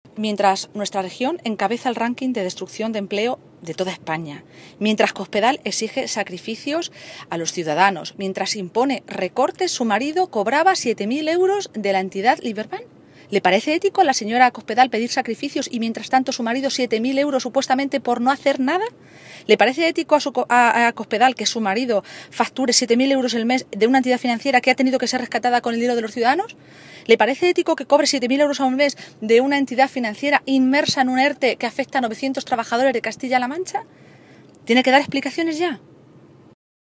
Cristina Maestre, Vicesecretaria y portavoz del PSOE de Castilla-La Mancha
Cortes de audio de la rueda de prensa